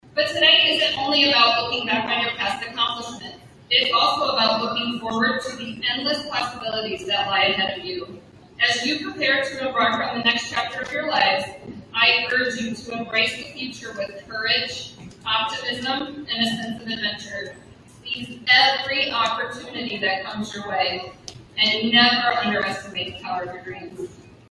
The 137th Commencement for Bronson High School was moved back inside of the high school gymnasium after being held outside the last few years.